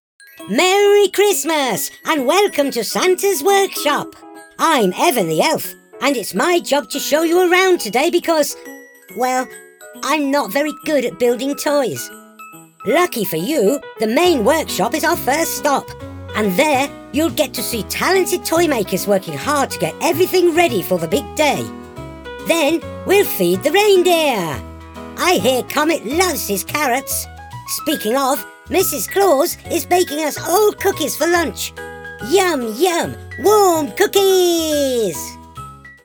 I have the flexibility to vary colour, tone, pitch, and shape for character voices.
Christmas Elf